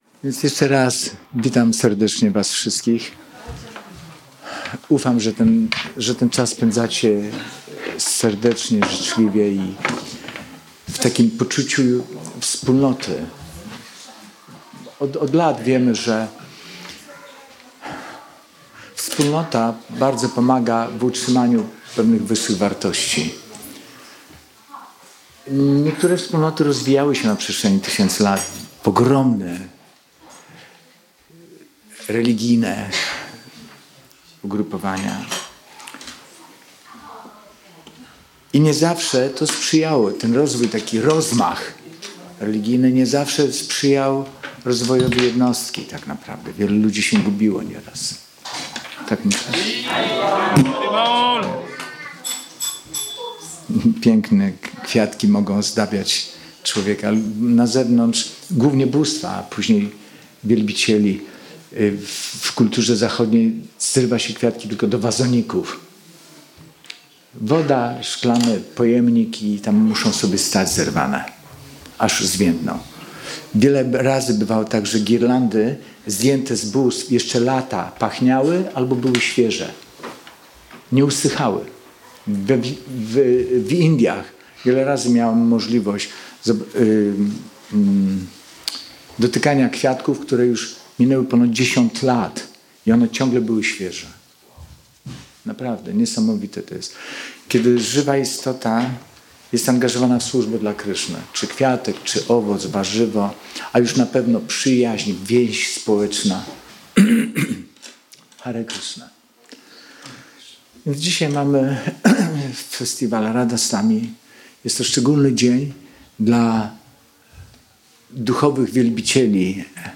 Wykład wygłoszony z okazji święta Radhasthami 31 sierpnia 2025 roku.